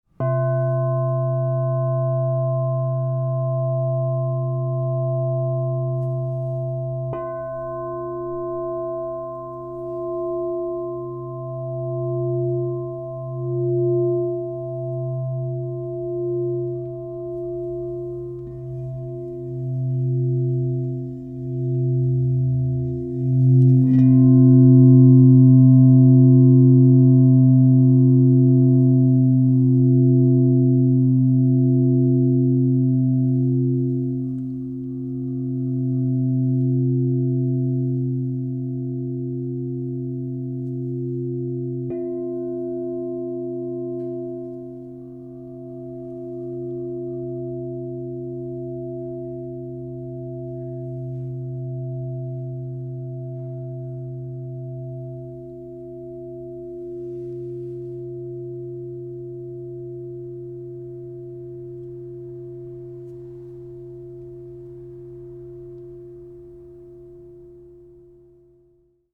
Crystal Tones® Palladium 12 Inch C Alchemy Singing Bowl
The expansive 12-inch size delivers rich and resonant tones, perfect for group sound healing sessions, personal practice, or enhancing sacred spaces.
Enhance your journey with 12″ Crystal Tones® alchemy singing bowl made with Palladium, Pink Aura Gold in the key of C -15.
432Hz (-)